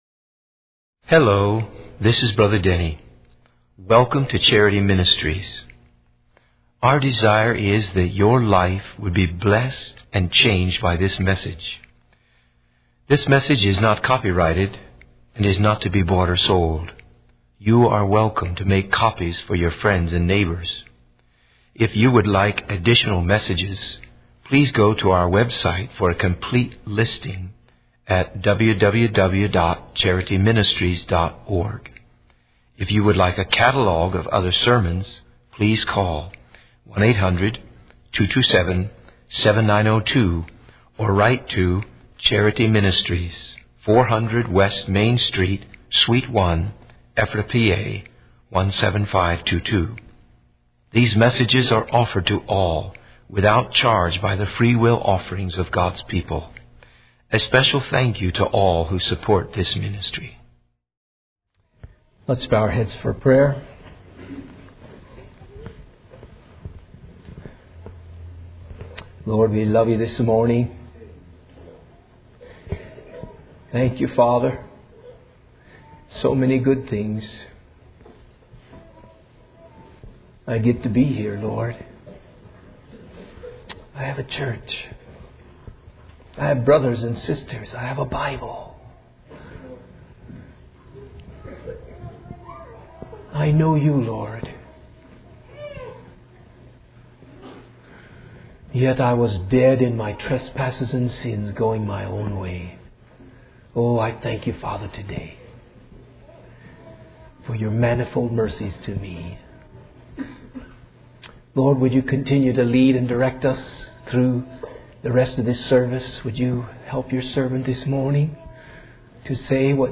In this sermon, the preacher emphasizes the importance of discerning the will of God in our lives. He highlights four points to help us in this process. The first point is to seek the witness of the Holy Spirit, allowing Him to guide and speak to our hearts. The second point is to align our desires with God's will, surrendering our own plans and ambitions.